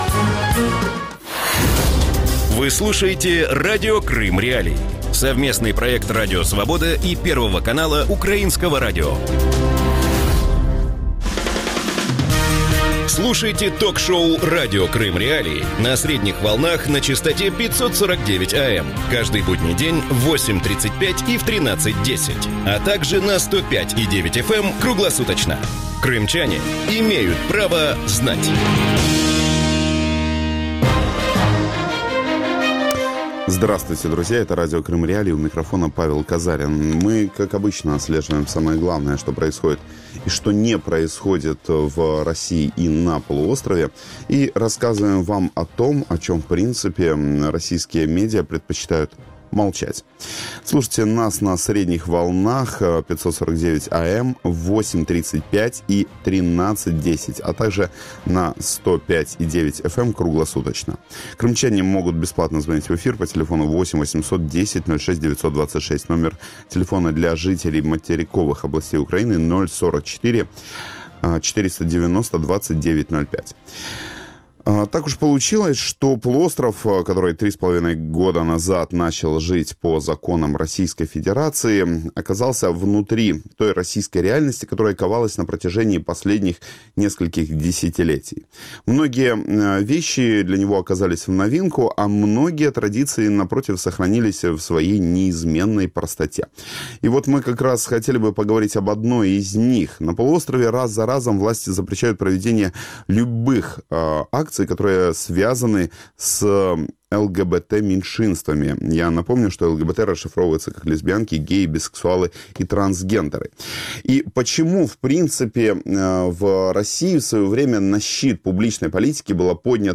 Радио Крым.Реалии эфире 24 часа в сутки, 7 дней в неделю.